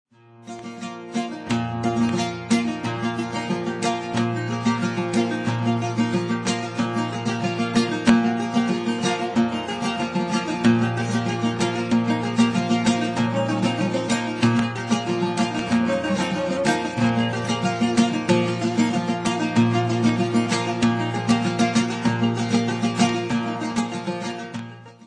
timeless Armenian songs for dancing or listening
master musician and singer
"America's Oud Virtuoso".